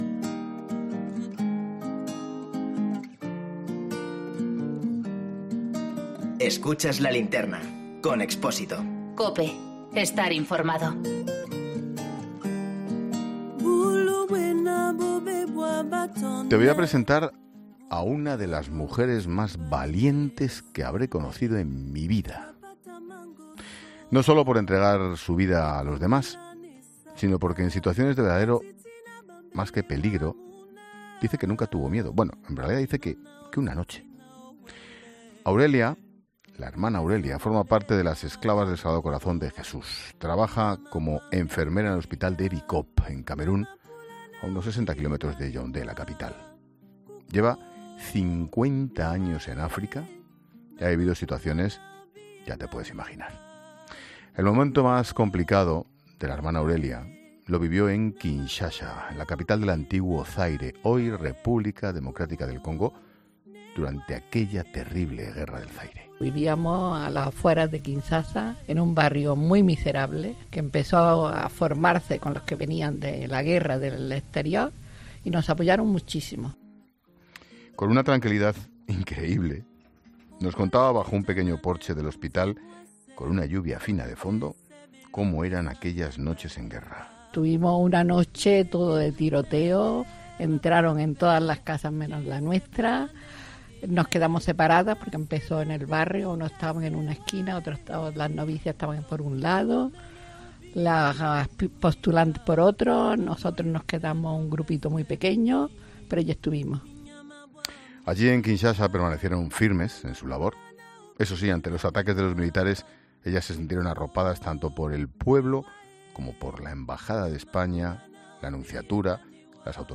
Con una tranquilidad envidiable, contaba bajo un pequeño porche del hospital, con una lluvia fina de fondo, como eran aquellas noches de guerra